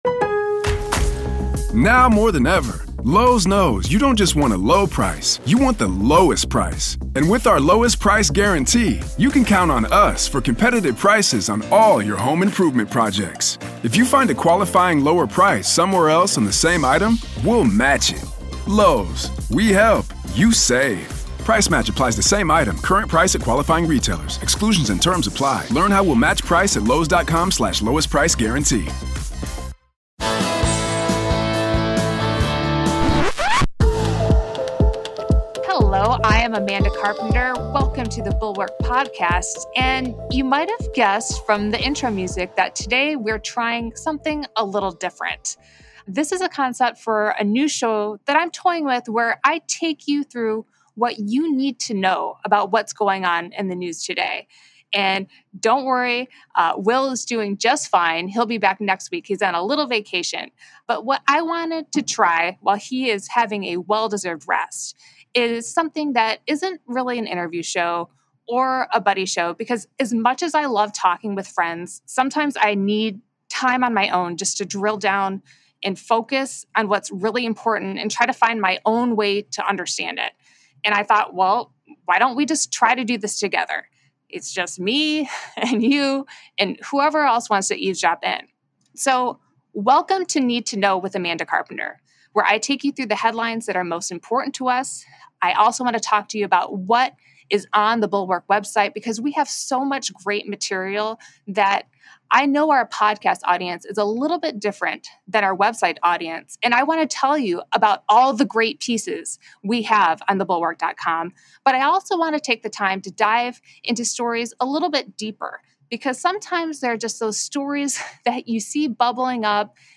Liz Cheney is campaigning against election deniers, Republicans were too arrogant about a red wave, and Trump's new made-for-TV legal team. Plus, a deep dive into the Georgia election interference investigation. Amanda Carpenter flies solo today — listen and share your feedback!